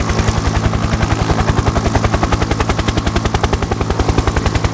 heli_loop.ogg